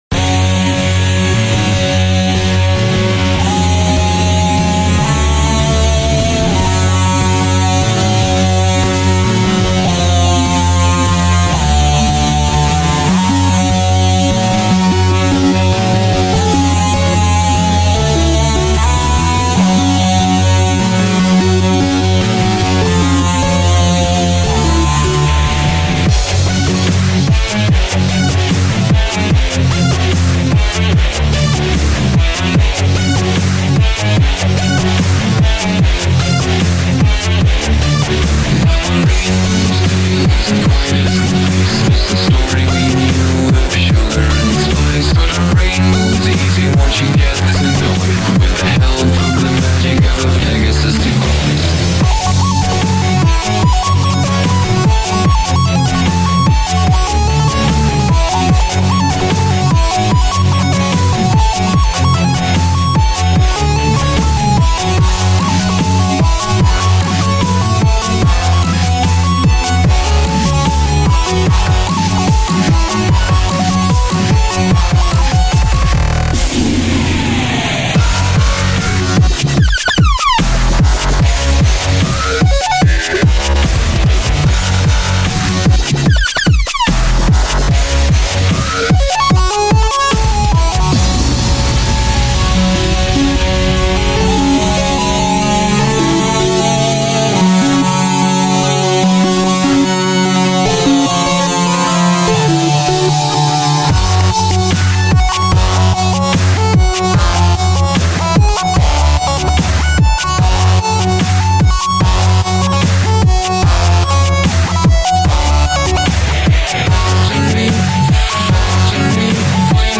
genre:metal